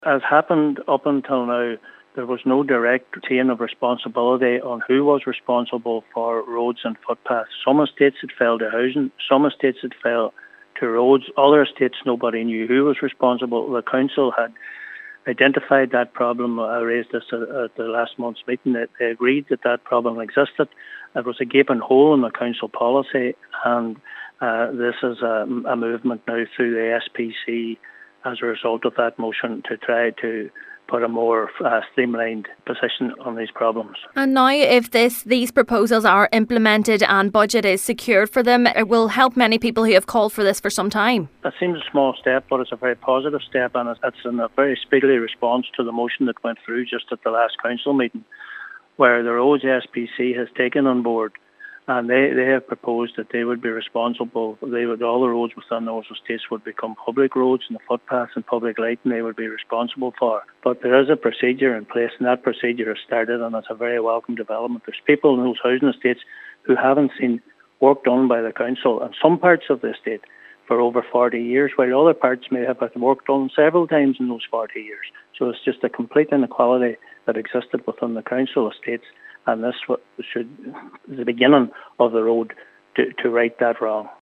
Councillor Crawford says this is a small but significant step: